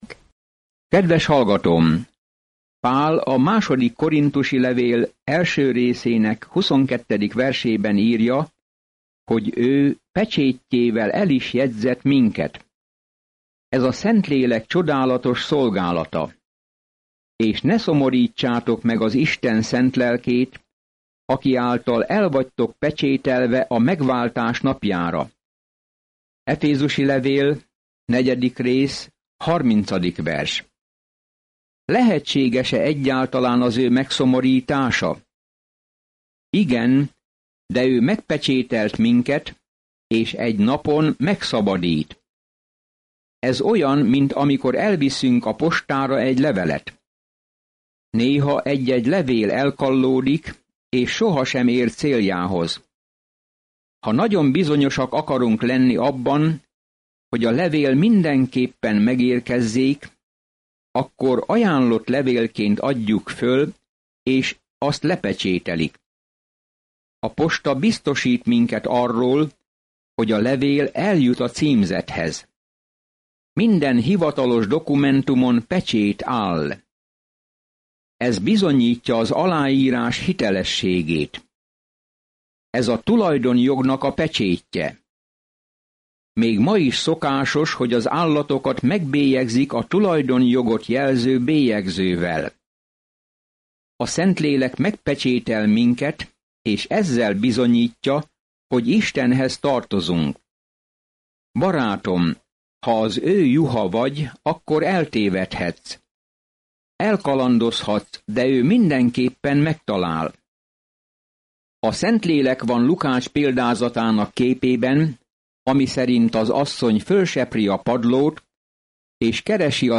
Napi utazás a 2 korinthusi levélben, miközben hallgatod a hangos tanulmányt, és olvasol válogatott verseket Isten szavából.